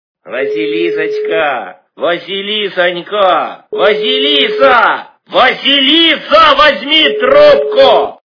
- Именные звонки